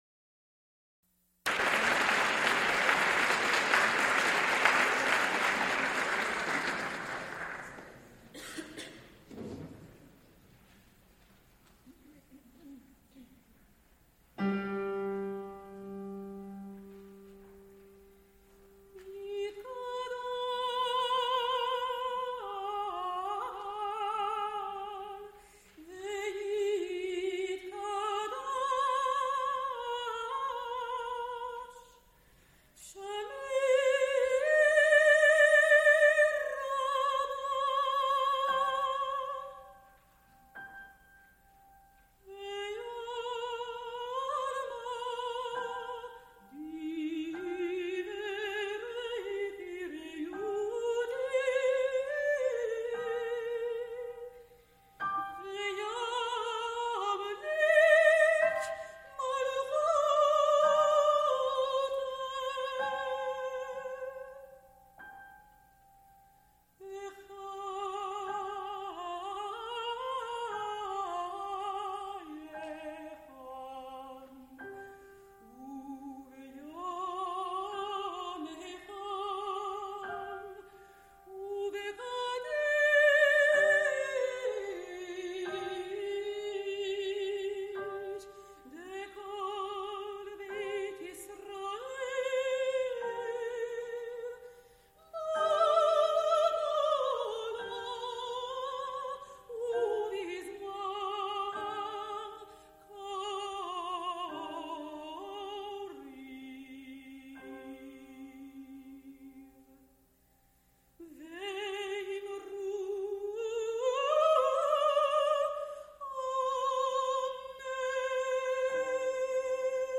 Chamber music for voice and instruments | Digital Pitt
mezzo soprano
flute
piano
Recorded live May 26, 1977, Frick Fine Arts Auditorium, University of Pittsburgh.
Extent 2 audiotape reels : analog, half track, 7 1/2 ips ; 7 & 12 in.
Songs (Medium voice) with instrumental ensemble